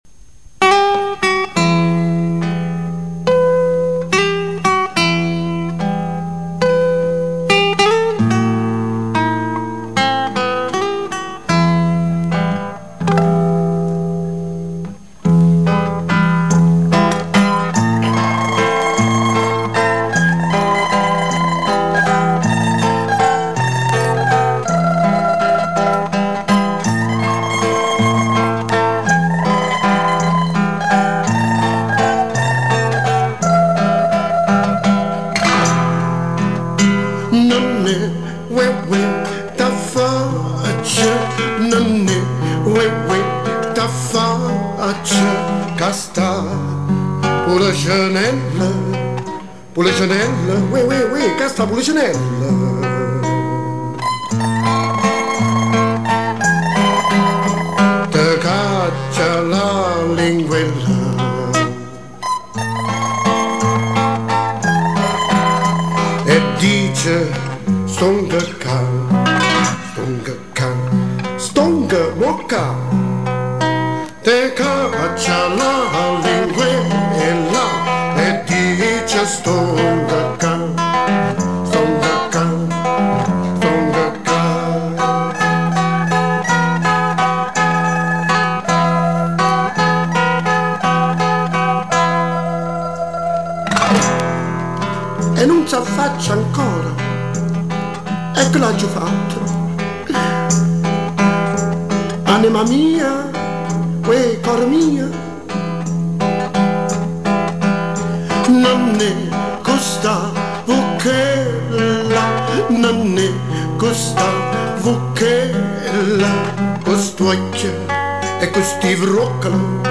Canzoni classiche vesuviane